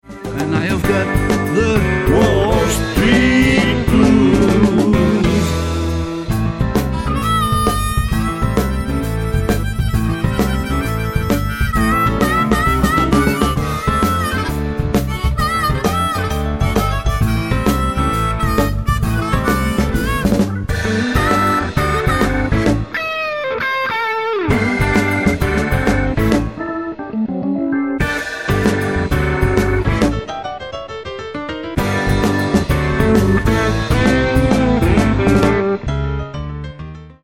Coverversion